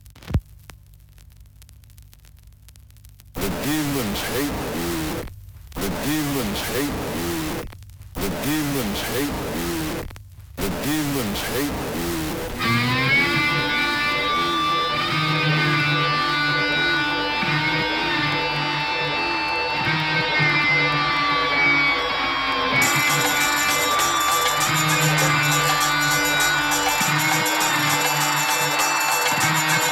• Metal